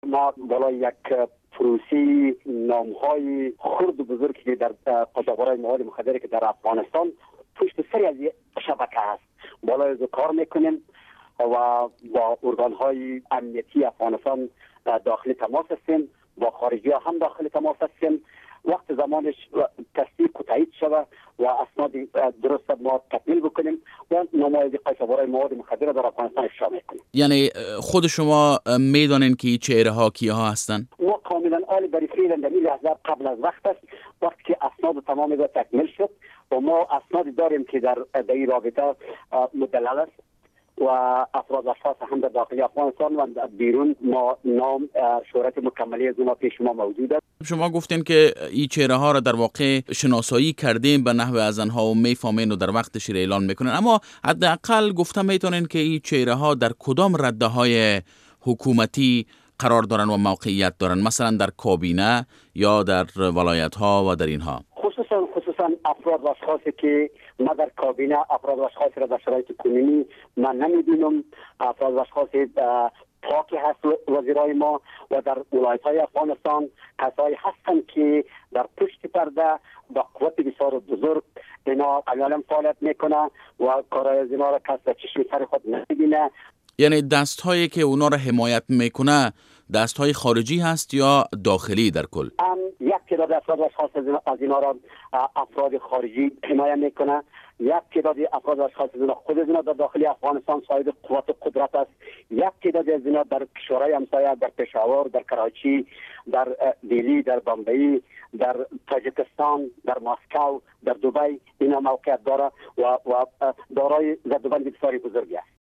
مصاحبه با جنرال خدایداد سرپرست وزارت مبارزه با مواد مخدر افغانستان